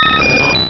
Cri de Roucoups dans Pokémon Diamant et Perle.